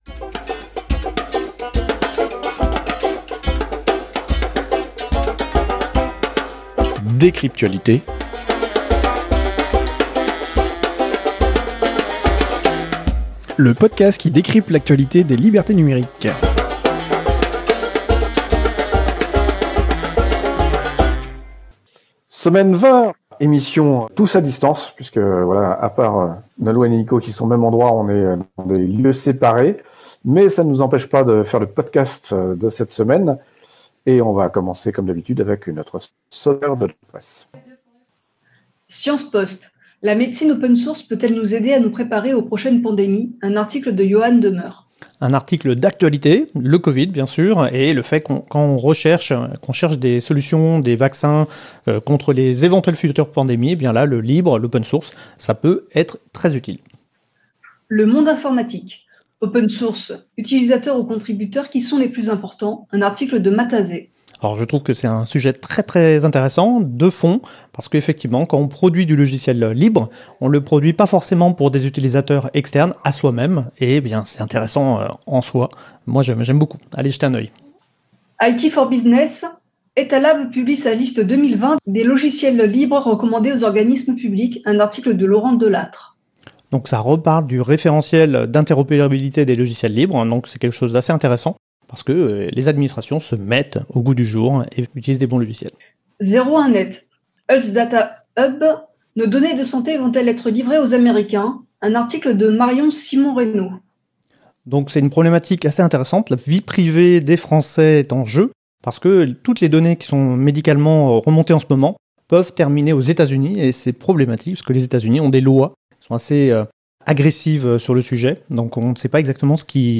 Studio d'enregistrement